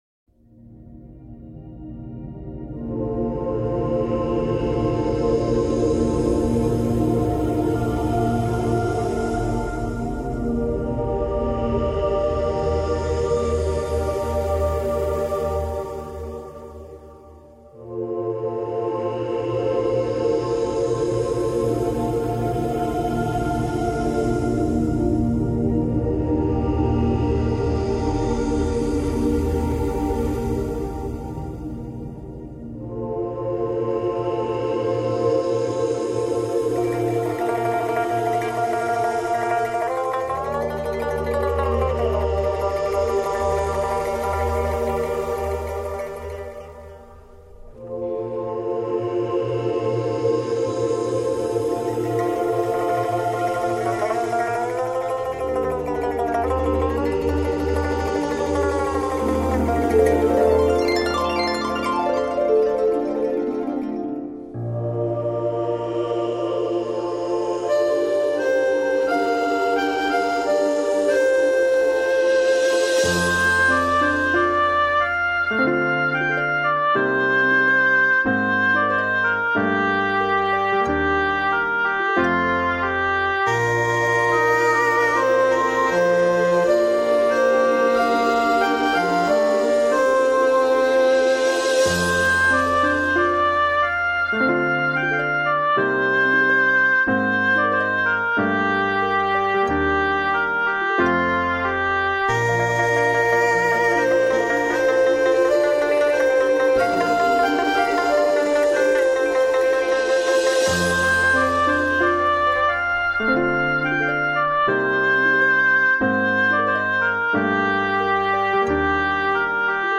版本：佛教轻音乐．全套四张
中國樂器的風韻在禮讚之中找到它的新衣